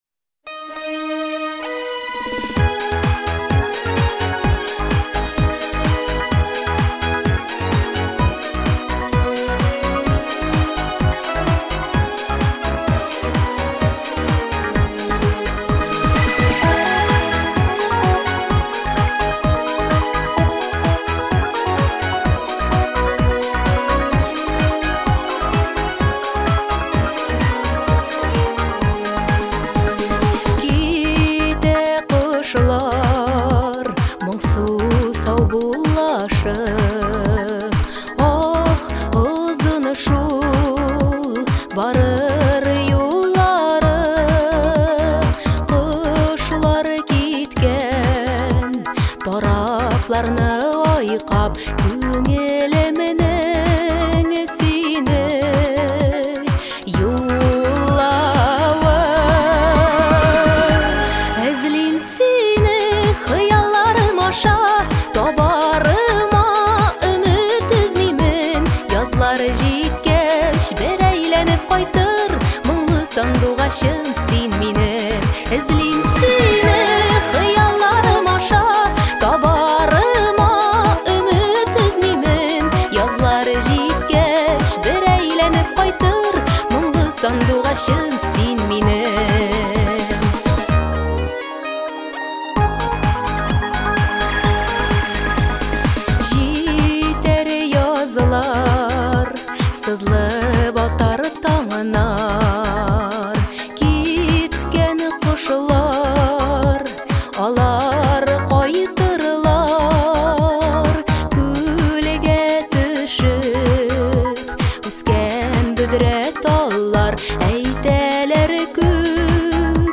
• Категория: Детские песни
татарские детские песни